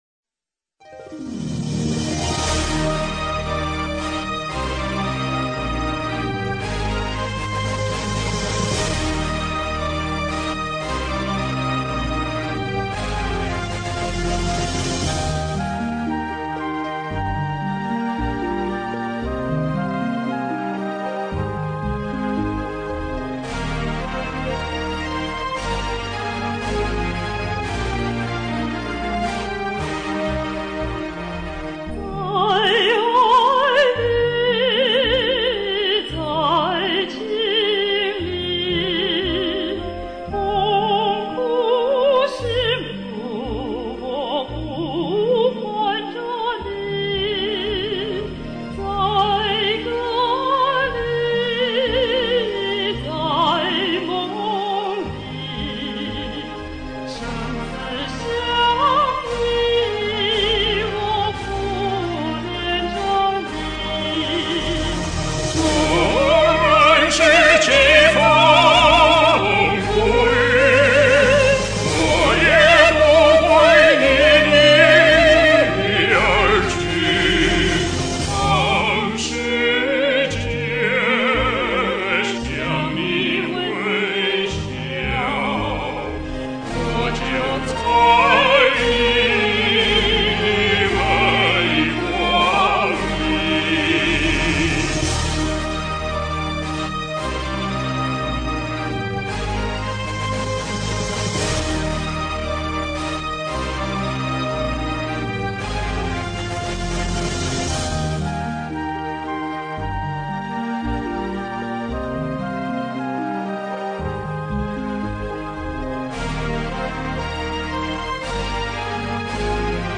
gonghe_Duet_Mix.mp3